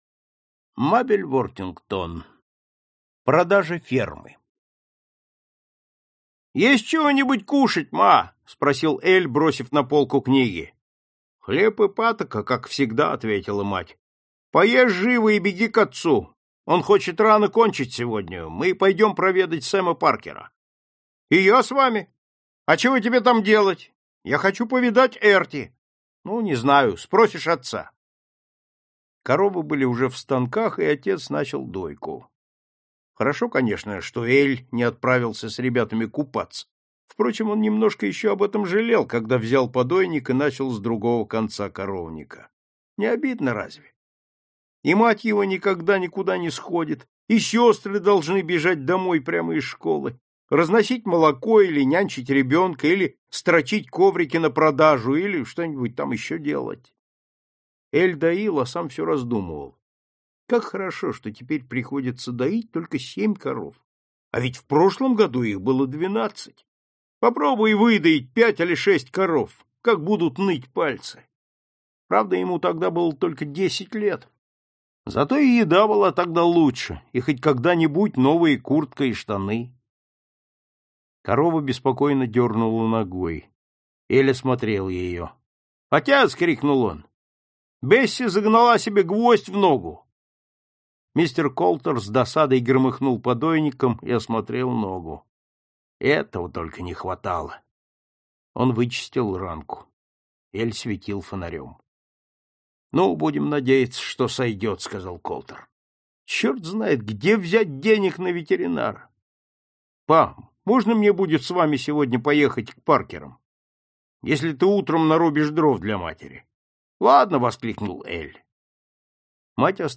Аудиокнига Классика зарубежного рассказа № 16 | Библиотека аудиокниг